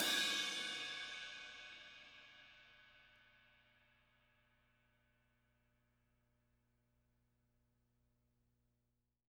R_B Crash B 02 - Close.wav